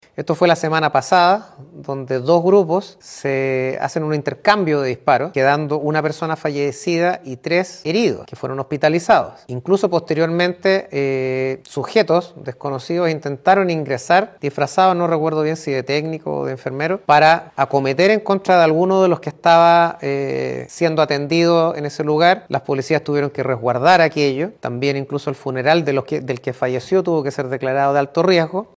Así lo informó el Fiscal de Análisis Criminal de O’Higgins, Carlos Fuentes